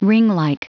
Prononciation du mot ringlike en anglais (fichier audio)
Prononciation du mot : ringlike